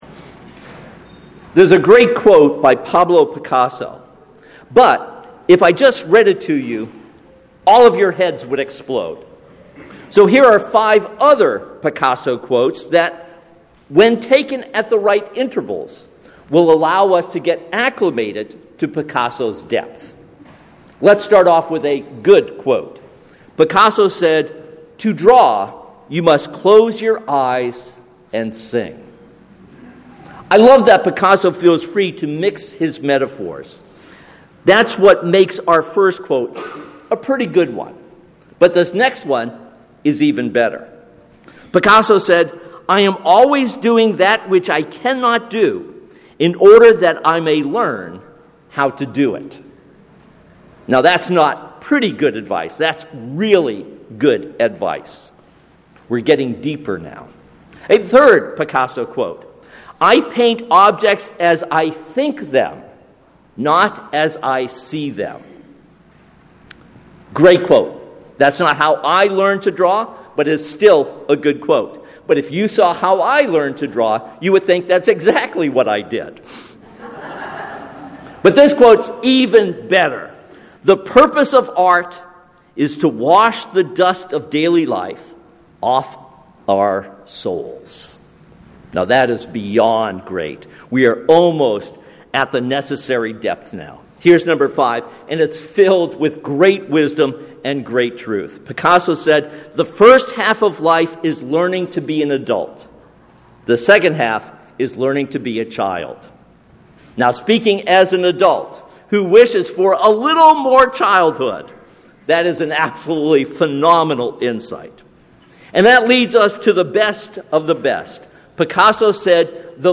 This sermon is based on Luke 6:32-38.